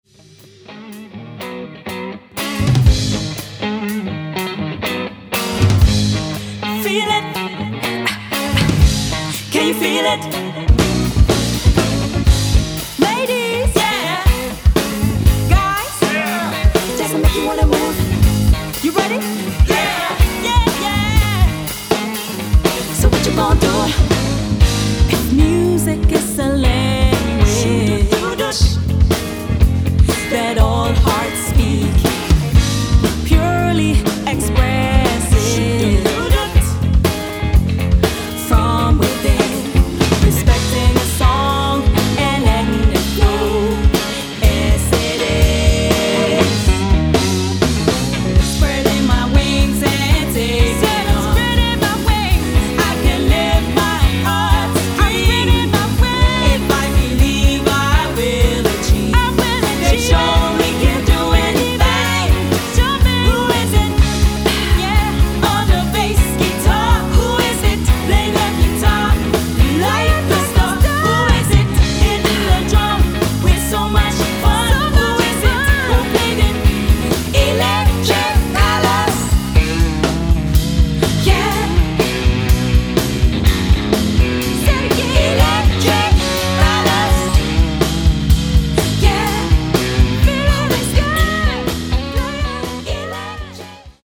Rock&Blues